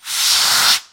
extinguisher.2.ogg